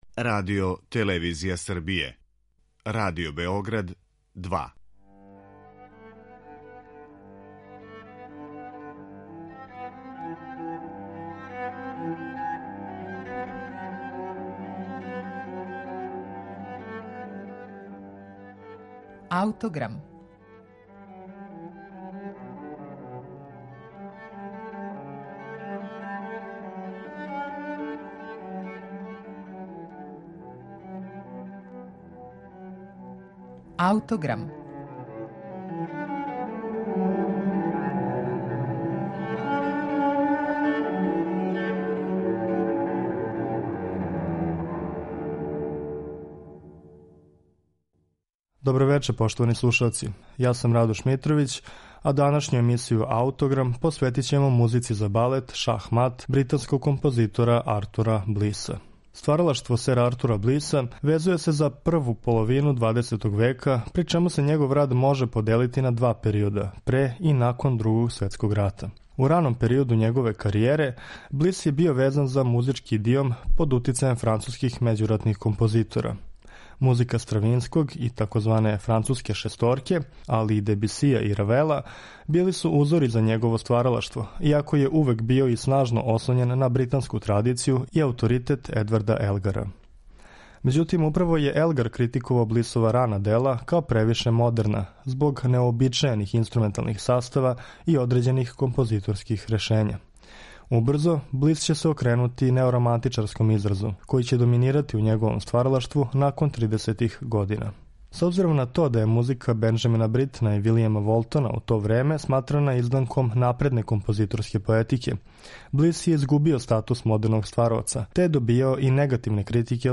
Музика за балет